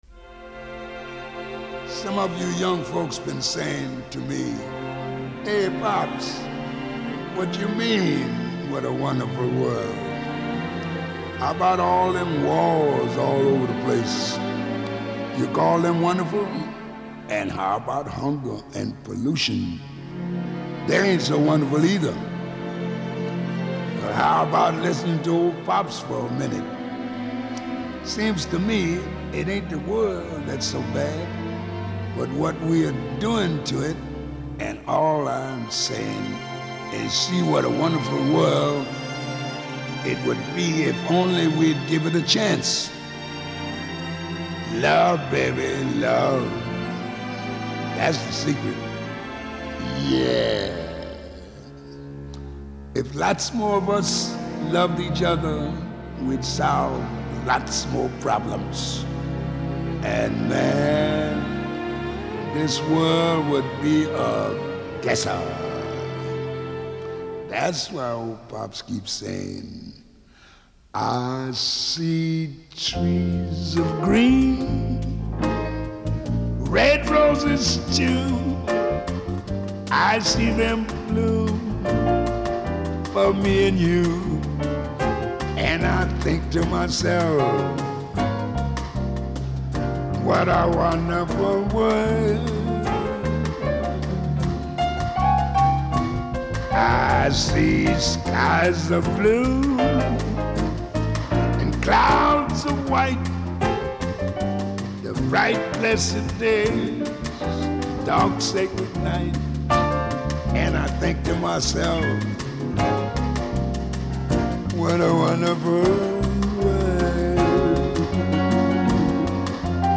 이건 라이브 버전입니다.
인트로로 나오는 읊조림이 정말 멋들집니다.